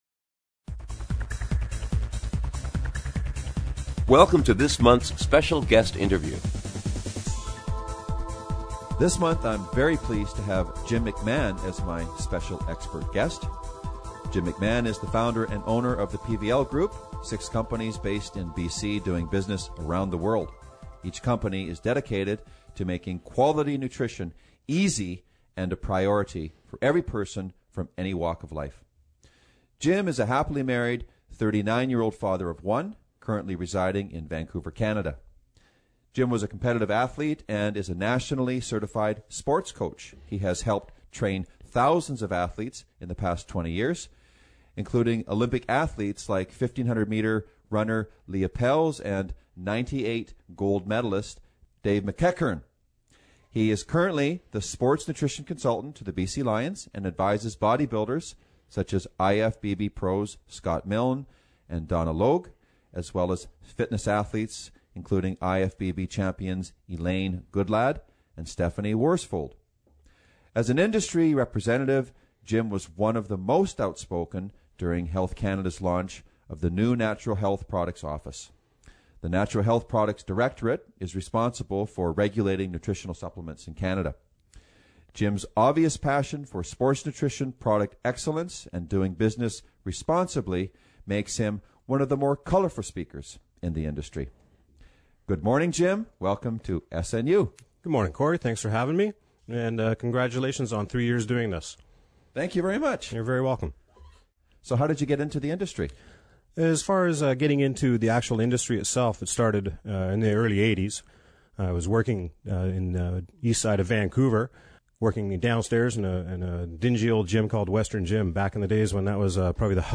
Special Guest Interview Volume 4 Number 1 V4N1c